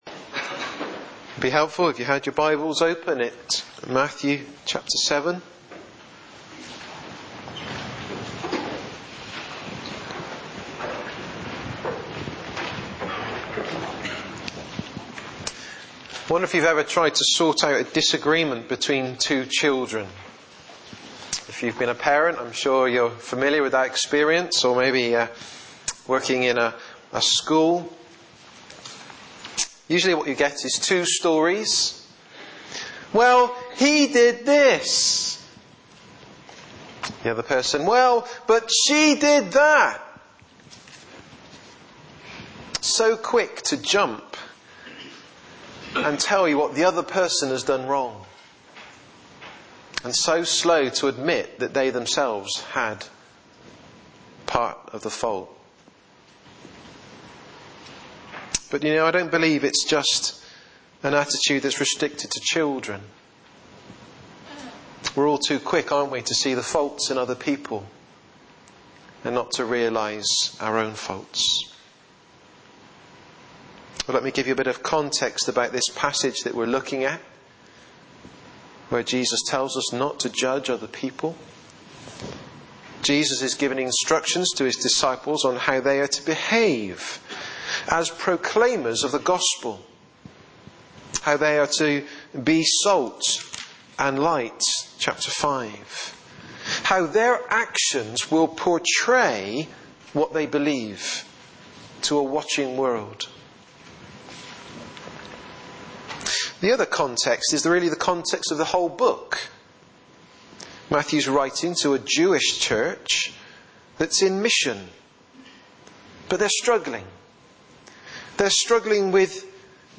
Sermons 2011